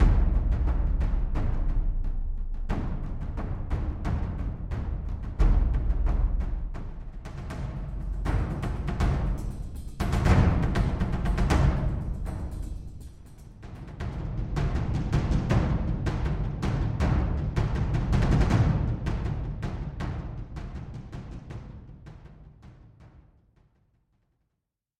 PERCUSIÓN EMOTIVA ÉPICA
• Ritmos potentes para acción, fondos sutiles y mucho más
BUCLES DE GRAN PEGADA